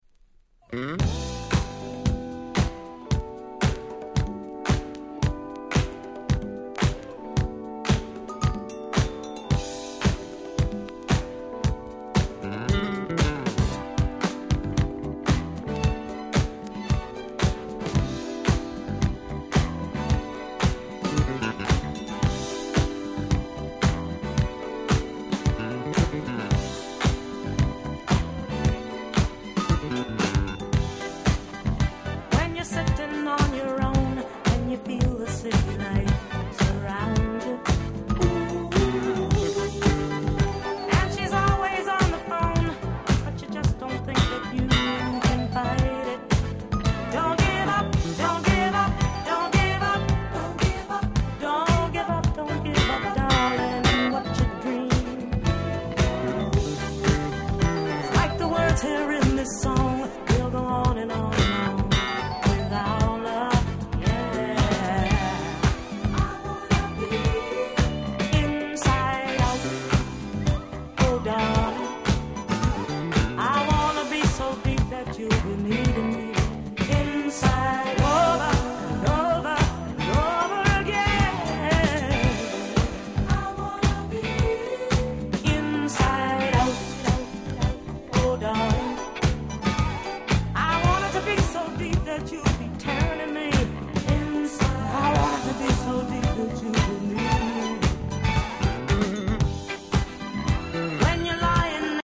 82' 鉄板Dance Classics !!